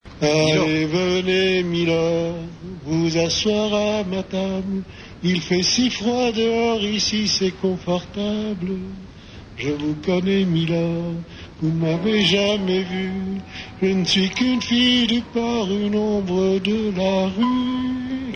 Émission " Le fou du roi " de Stéphan Bern - France Inter Mars 2002 -